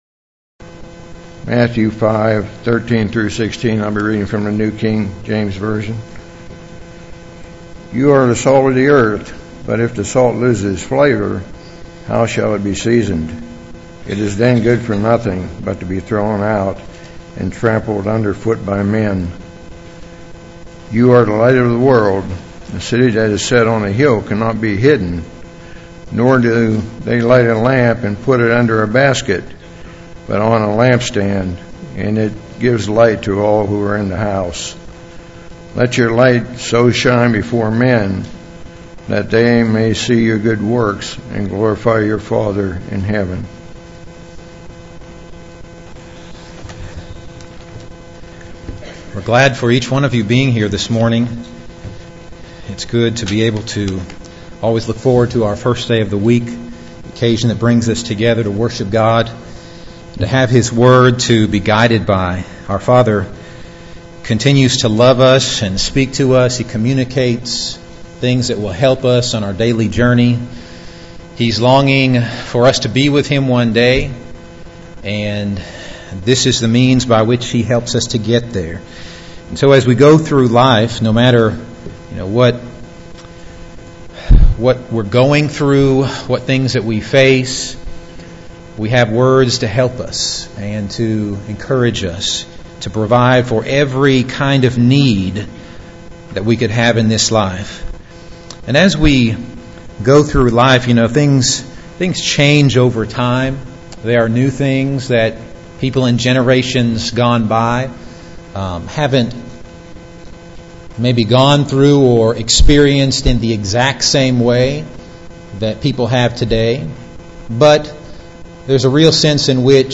Eastside Sermons Service Type: Sunday Morning « Wednesday Evening Youth Service